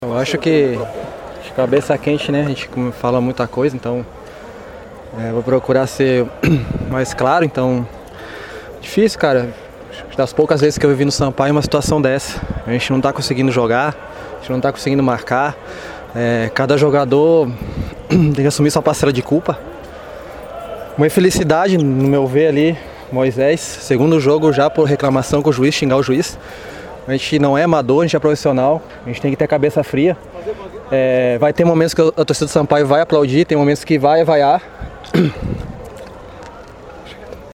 Em tom de sinceridade, os jogadores fizeram uma avaliação sobre o momento da equipe e todas as atuações abaixo da crítica.